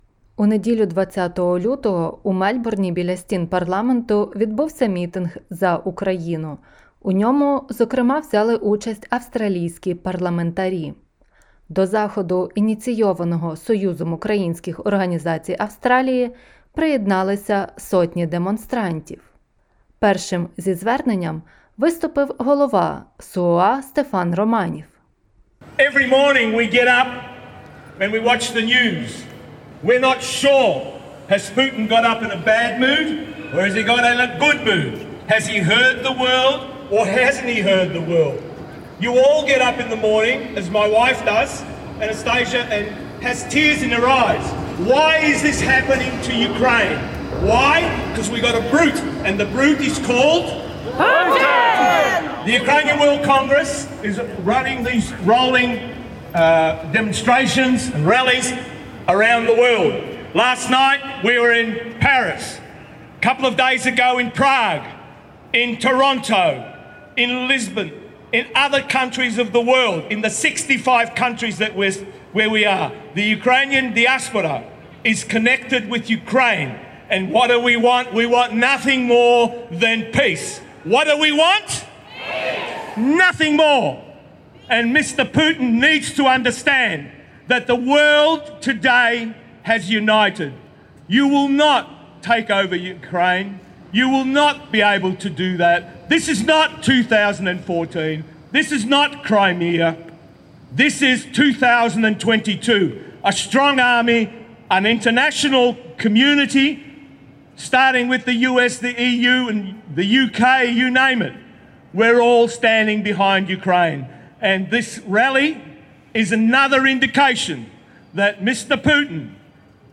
Hon Bill Shorten, Senator David Van and a Leader of the Victorian State Opposition Matthew Guy attended and spoke at the ‘Stand with Ukraine’ Rally in Melbourne. They showed their support for all Ukrainians and impressed the importance of the issue to Australians.